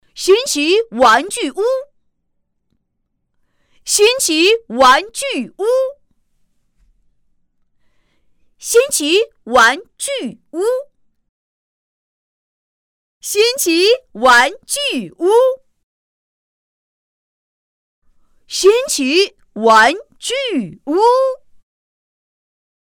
模仿童声 | 声腾文化传媒
【童声】男童女9.mp3